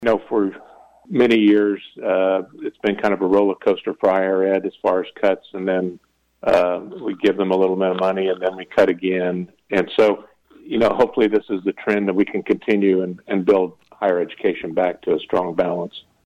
Following the wrap-up of the regular session last week, Schreiber joined KVOE’s Morning Show Wednesday to offer his thoughts.